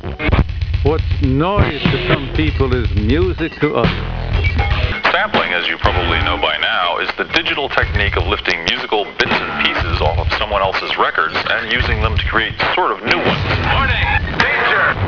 Sampling, Bass, DJ Cuts, Keyboards, Percussion
Keyboard Sampling Noise
Phone-In Message